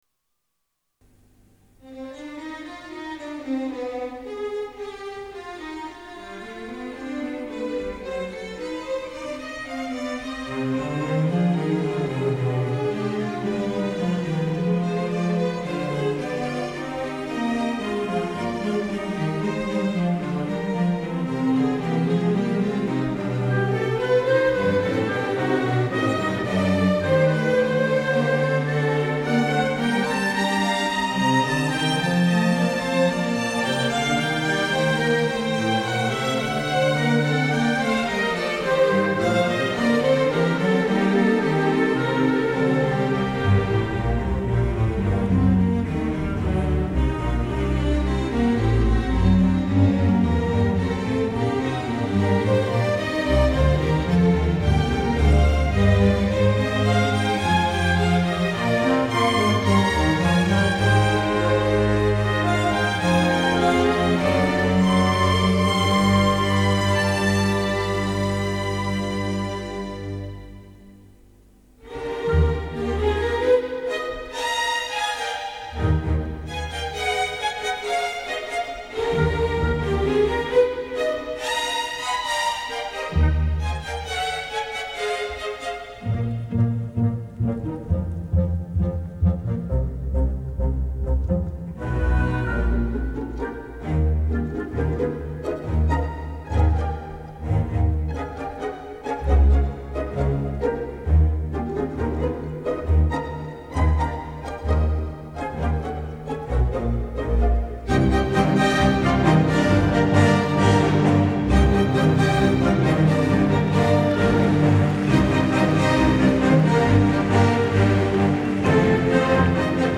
하모니 라이브버전
기타만 나오는 원곡과 다르게 피아노 섹소폰 베이스 드럼 기타 다 나옵니다